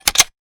weapon_foley_pickup_10.wav